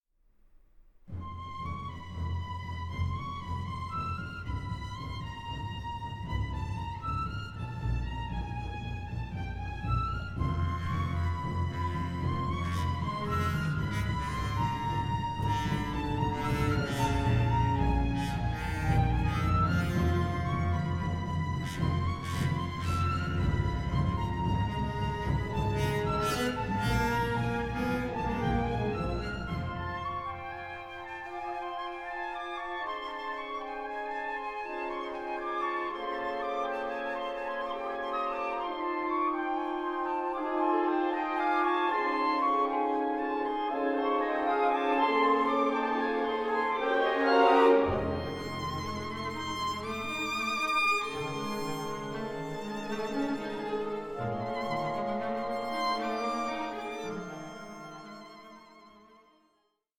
With orchestral textures both radiant and unsettling